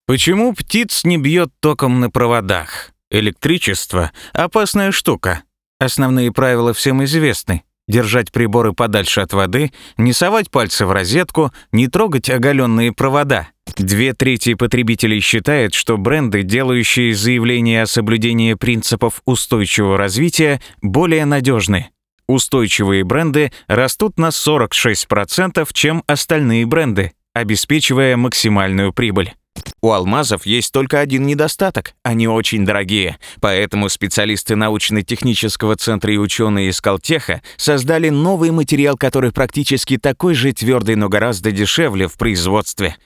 У микрофона с 2007 года Позитивный, искренний голос.
Тракт: Condenser microphone - cust. mic m7 caps. Channel Strip warmer sound m40& drawmer dl241